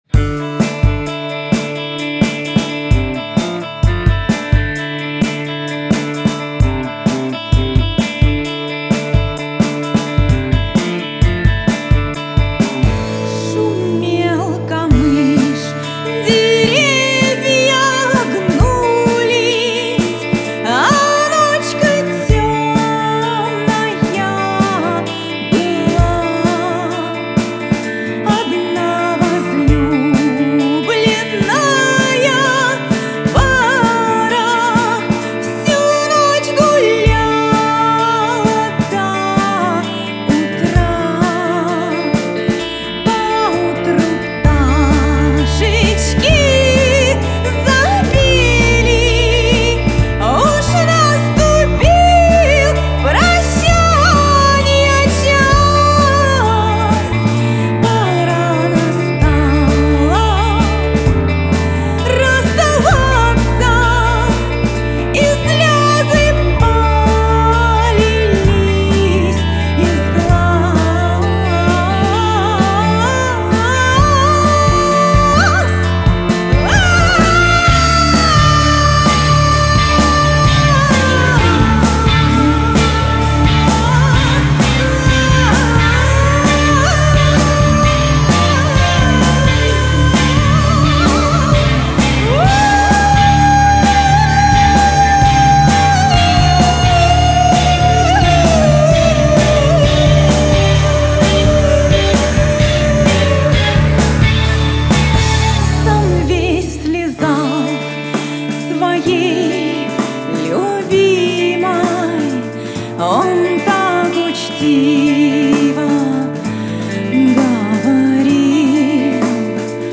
Genre: Folklore.